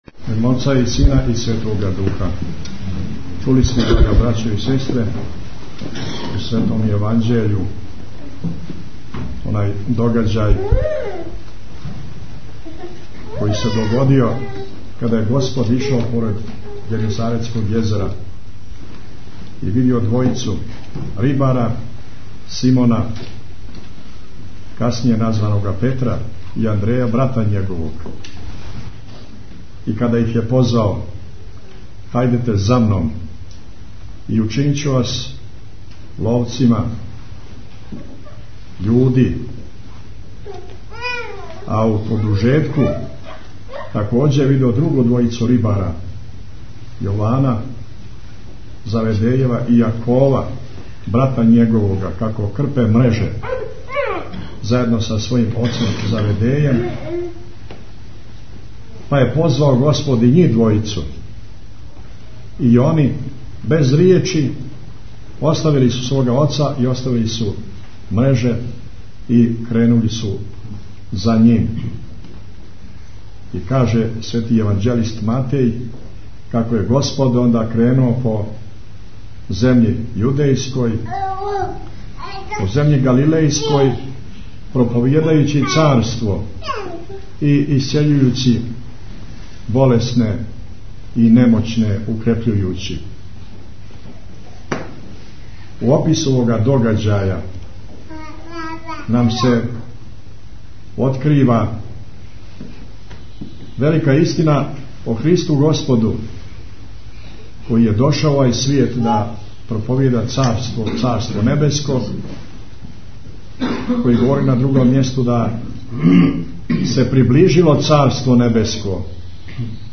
Бесједа Архиепископа Г. Амфилохија у Цетињском манастиру, 21. јун 2009 | Радио Светигора
Tagged: Бесједе Наслов: AEM Crnogorsko-primorski Amfilohije Албум: Besjede Година: 2009 Величина: 14:14 минута (2.45 МБ) Формат: MP3 Mono 22kHz 24Kbps (CBR) Бесједа Његовог Високопреосвештенства Архиепископа Цетињског Митрополита Црногорско - приморског Г. Амфилохија са Свете Архијерејске Литургије, коју је у недјељу 21. јуна служио у Цетињском манастиру.